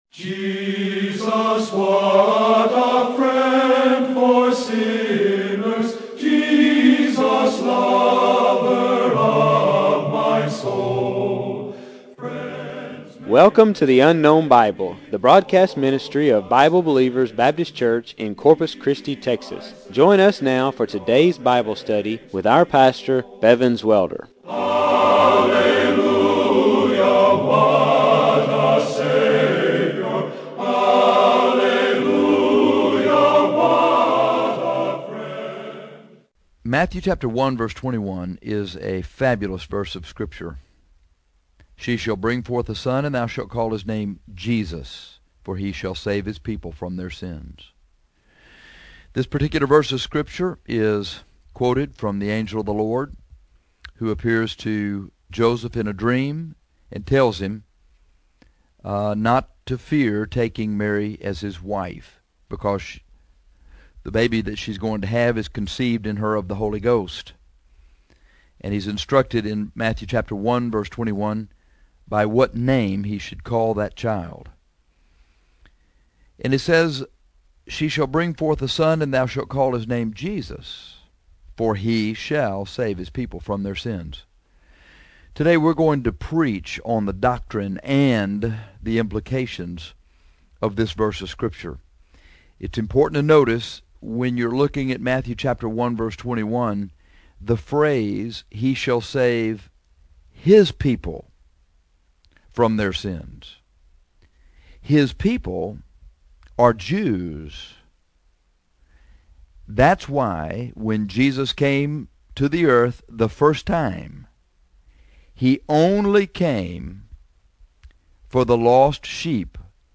Today we are going to preach on the doctrine and the implications of this verse of scripture.